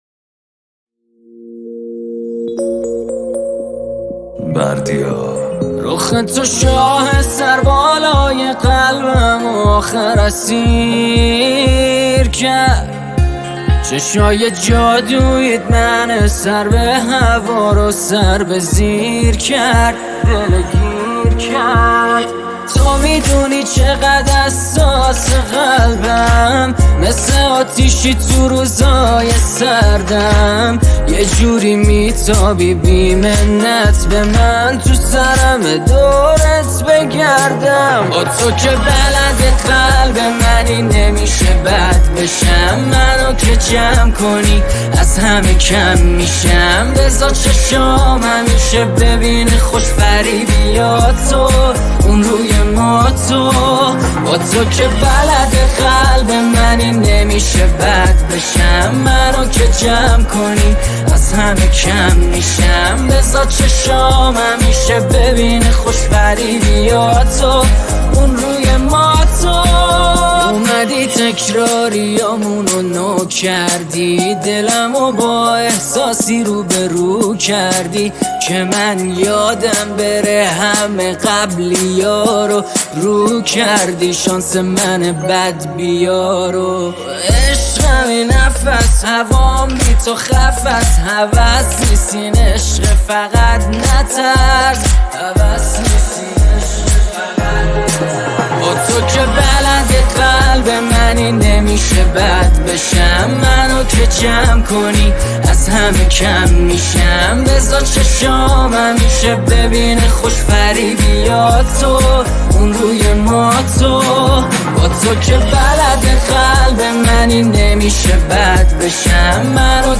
آهنگ فارسی